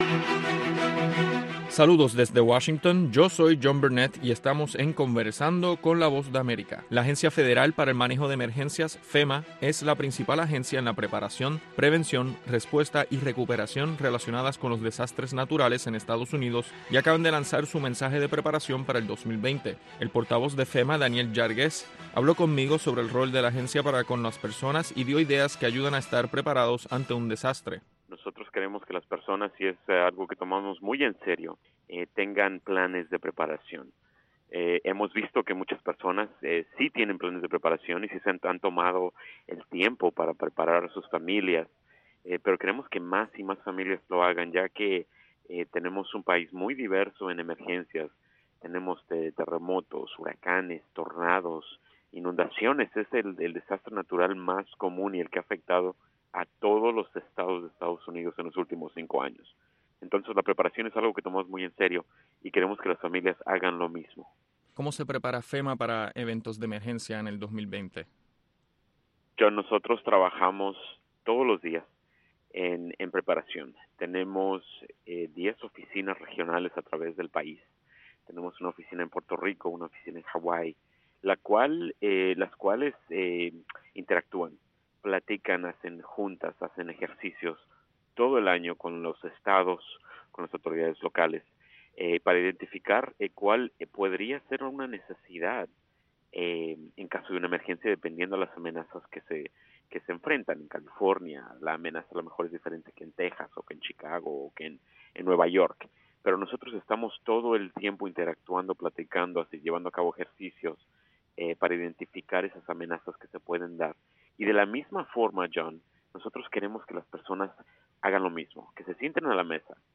La Voz de América entrevista, en cinco minutos, a expertos en diversos temas.